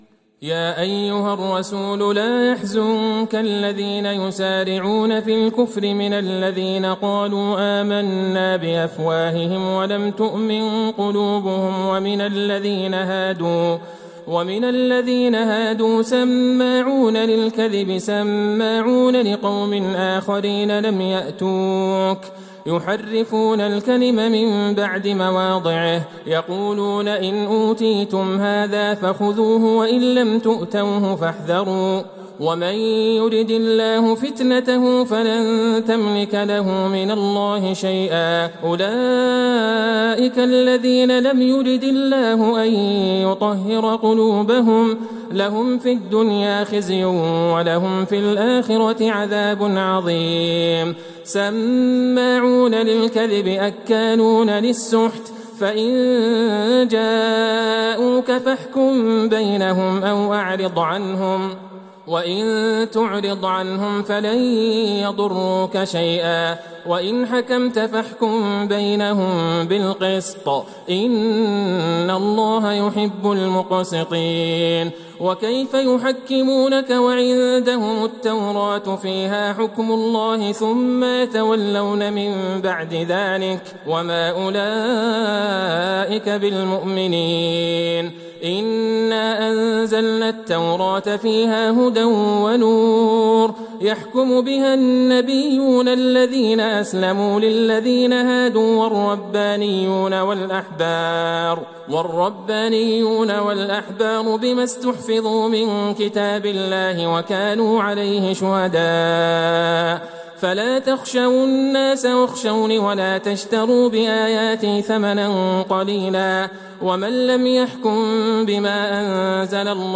ليلة ٨ رمضان ١٤٤١هـ من سورة المائدة { ٤١-٨١ } > تراويح الحرم النبوي عام 1441 🕌 > التراويح - تلاوات الحرمين